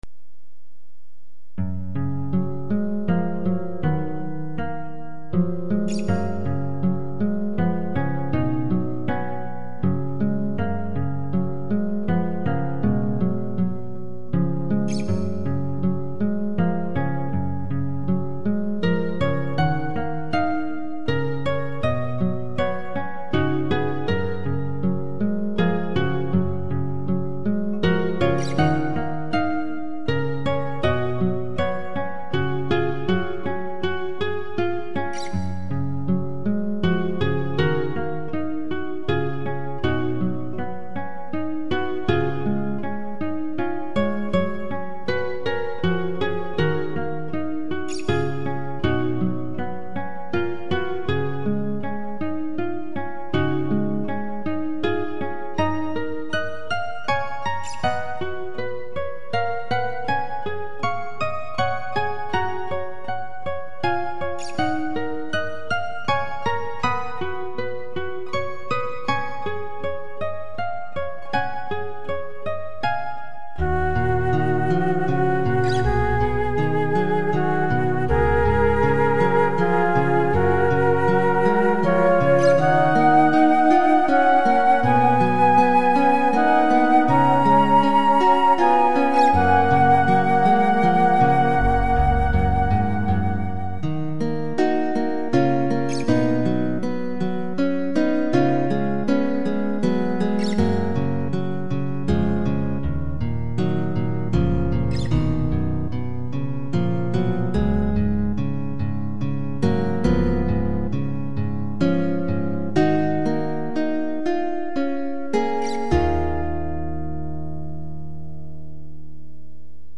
解説 初心者丸出しのフレットノイズがうるさい…消そうかな ご意見募集。